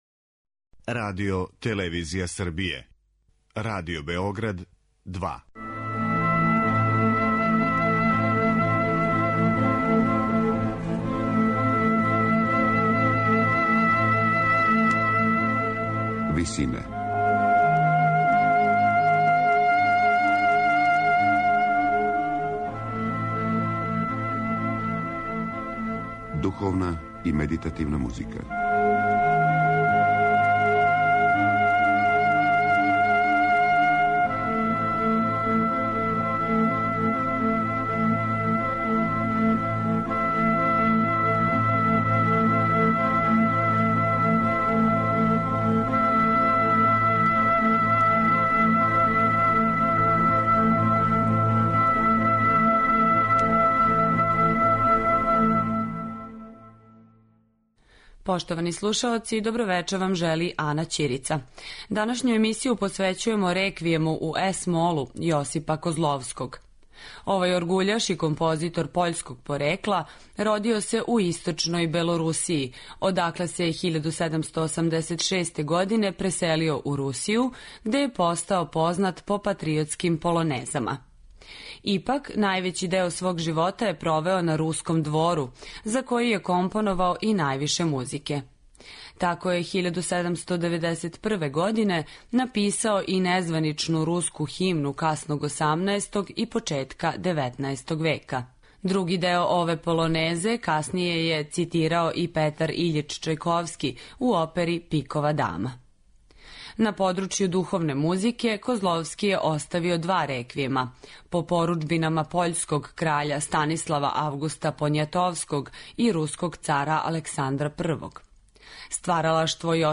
Он је аутор првог реквијема у Русији. Написан је за солисте, хор и оркестар, а премијерно изведен 25. фебруара 1798. године у Санкт Петербургу.